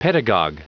Prononciation du mot pedagog en anglais (fichier audio)
Prononciation du mot : pedagog